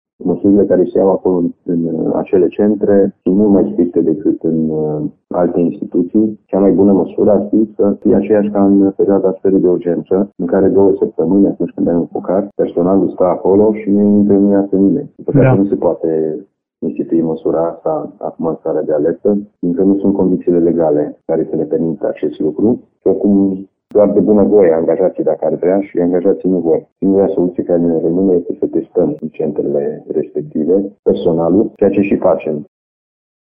Liderul CJ Timiș spune, însă, că legislația nu permite acest lucru, așa că tot ce se poate face este testarea regulată.